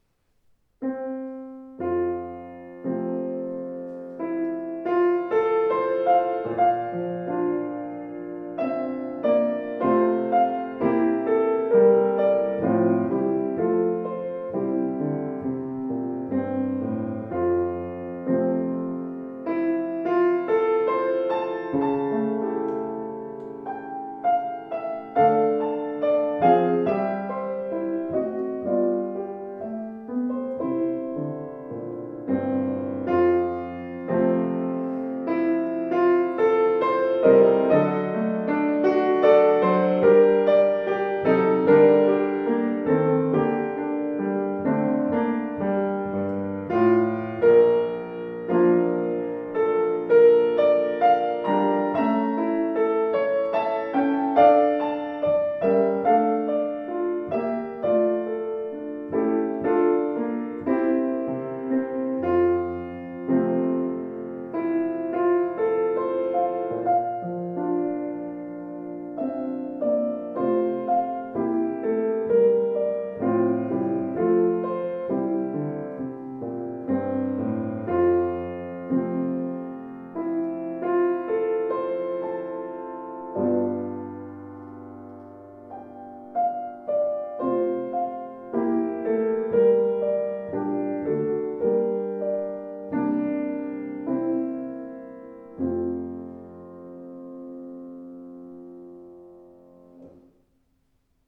strahlender, gestaltungsfähiger Klang